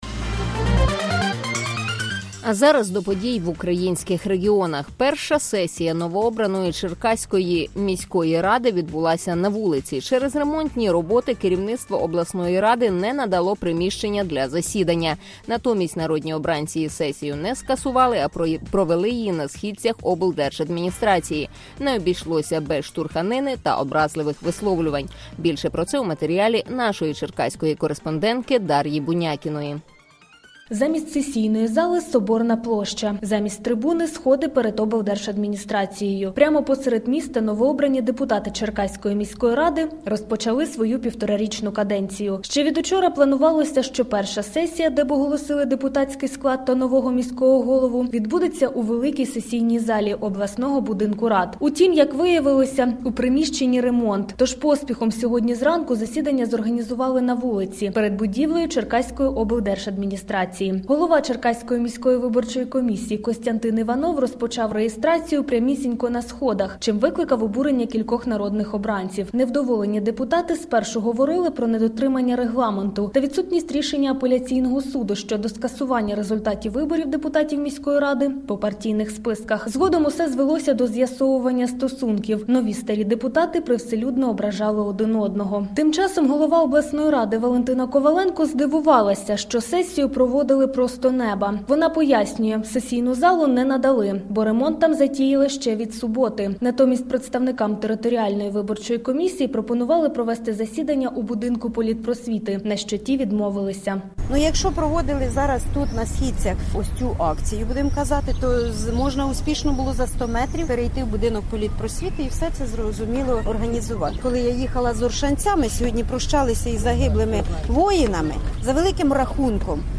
Перша сесія Черкаської міськради відбулася на вулиці. Була штурханина